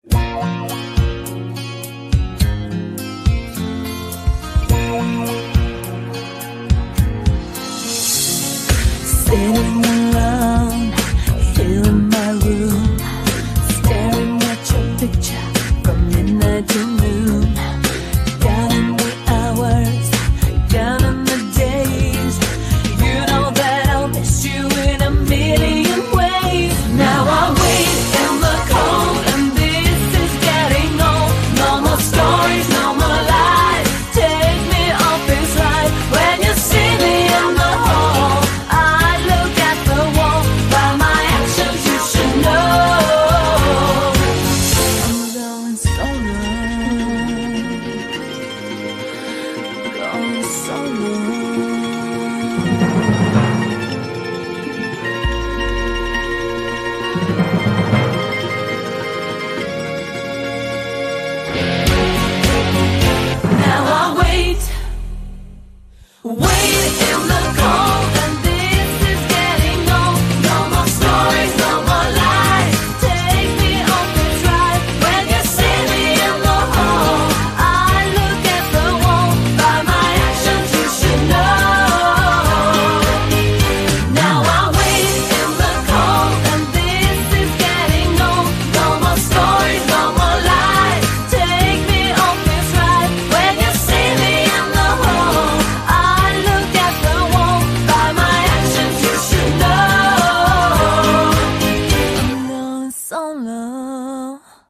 BPM52-210
Audio QualityPerfect (High Quality)
Comments*The real minimum BPM is 52.5